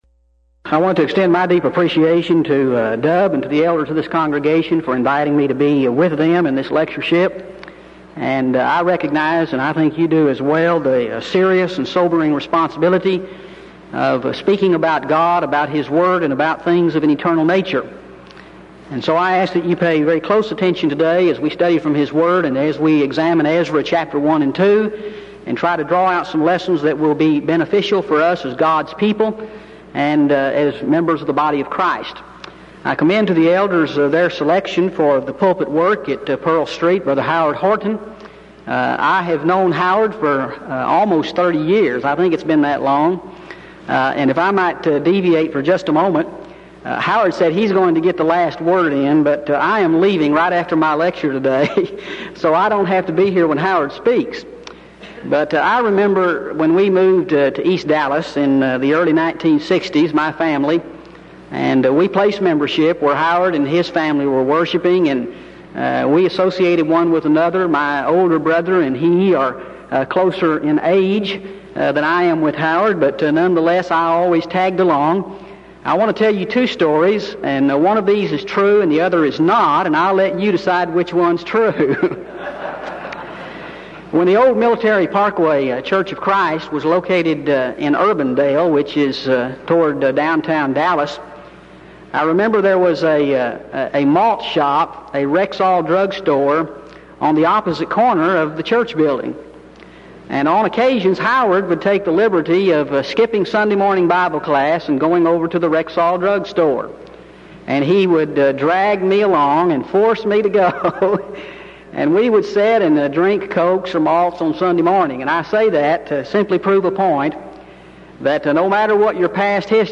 Event: 1992 Denton Lectures Theme/Title: Studies In Ezra, Nehemiah And Esther